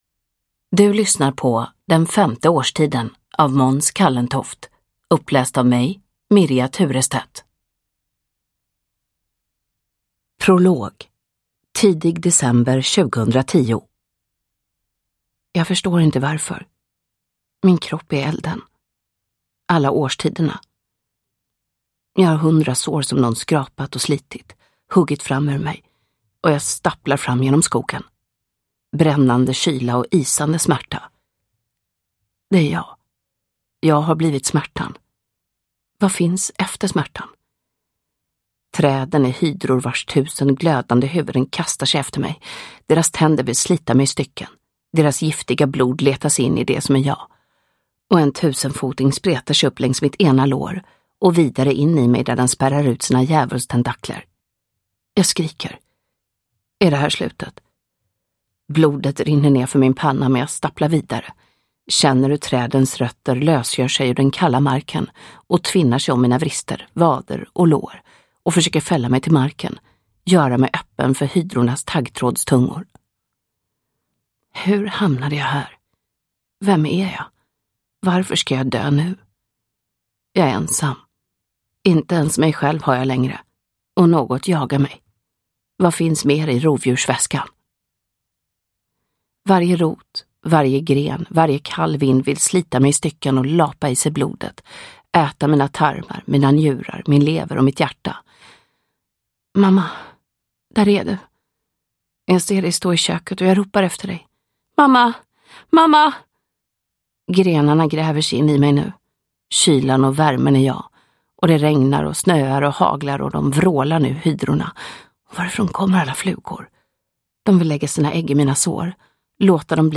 Uppläsare: Mirja Turestedt
Ljudbok